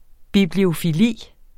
Udtale [ bibliofiˈliˀ ]